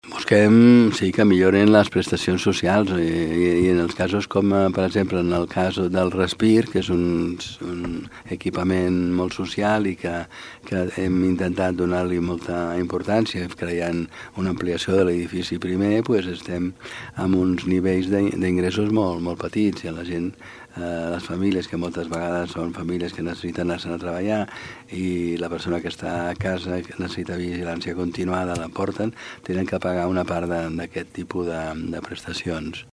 Aquestes millores, centrades especialment en oferir un millor servei del Respir, ajudarien a finançar el servei d’atenció continuada que s’hi ofereix, i que actualment han d’assumir parcialment els familiars dels seus usuaris. Ho ha explicat l’alcalde Valentí Agustí.